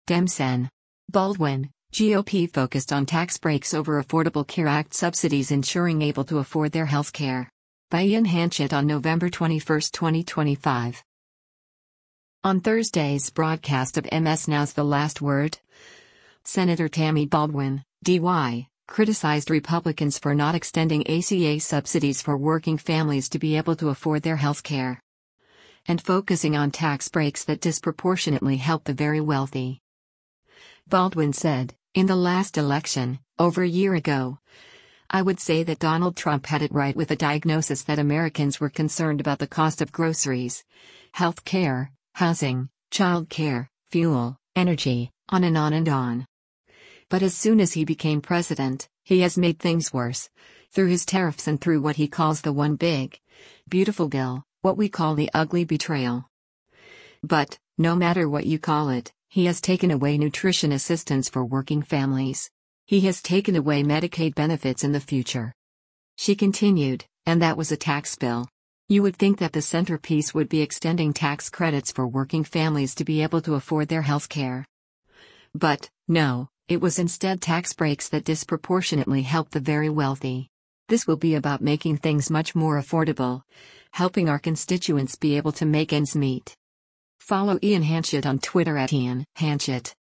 On Thursday’s broadcast of MS NOW’s “The Last Word,” Sen. Tammy Baldwin (D-WI) criticized Republicans for not extending ACA subsidies “for working families to be able to afford their health care.”